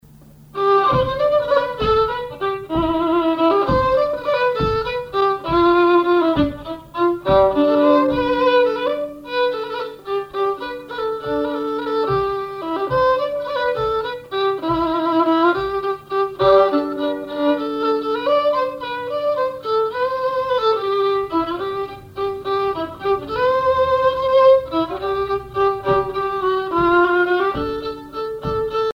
violoneux, violon
valse musette
instrumentaux au violon mélange de traditionnel et de variété
Pièce musicale inédite